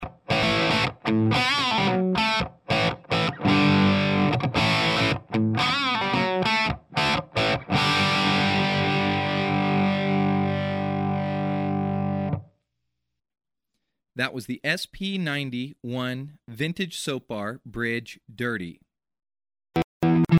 Ses médium boostés lui assurent une place sur les guitares des rockeurs psyché & progressifs.
P90BridgeDirty.mp3